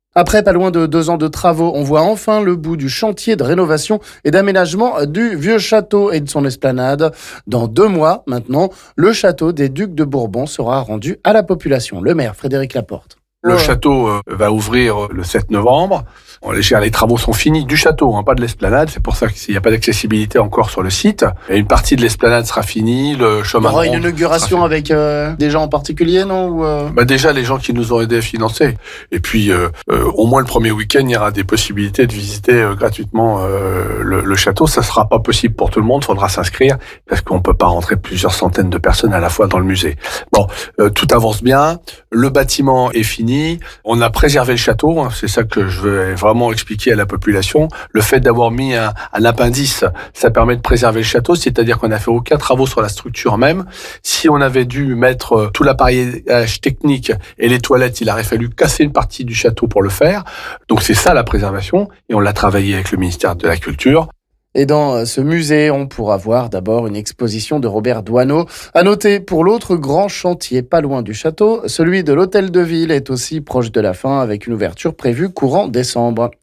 On écoute le maire Frédéric Laporte...